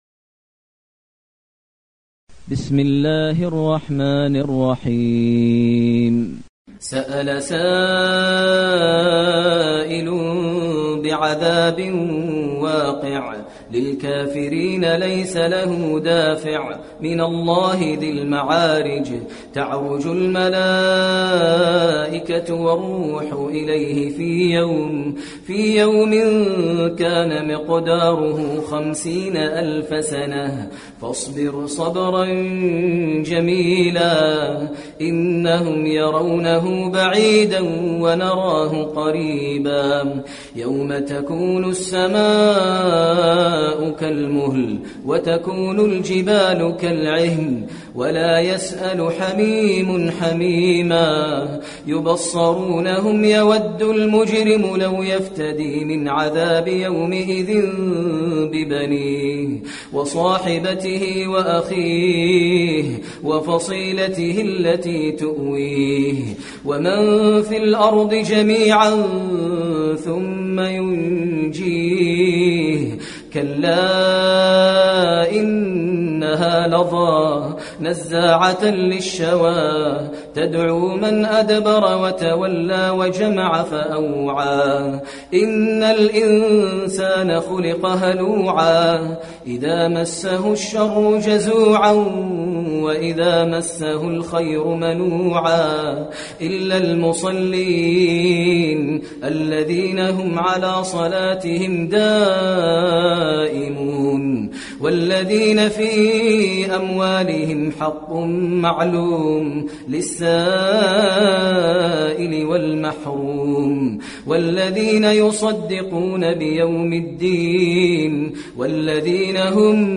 المكان: المسجد النبوي المعارج